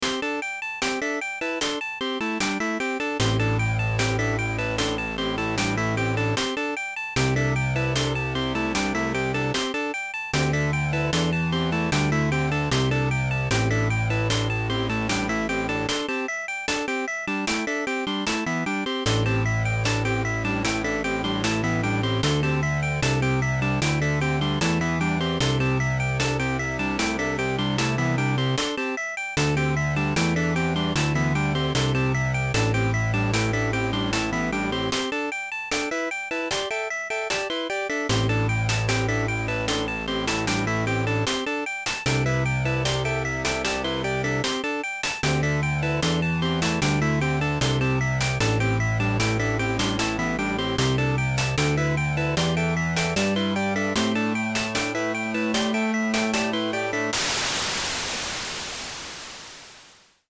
Download Pixel sound effect for free.